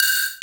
AGOGO_FX_HI.wav